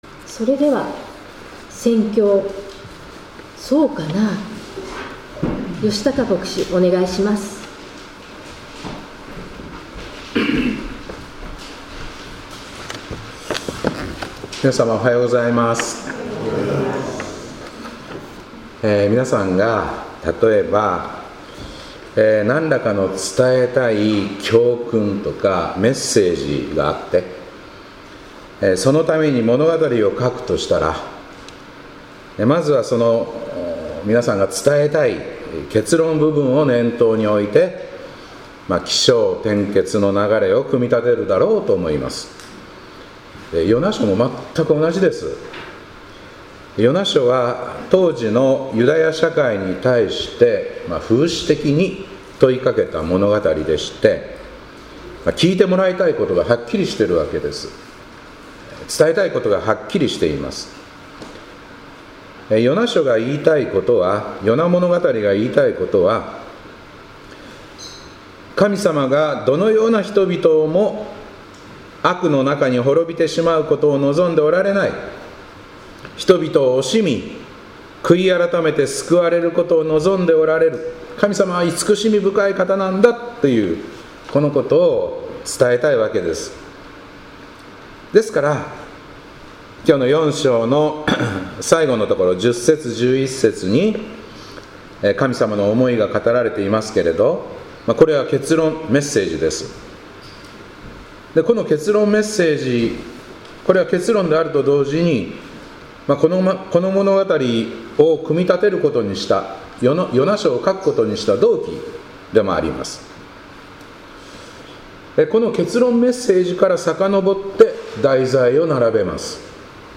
2025年10月26日礼拝「そうかなぁ」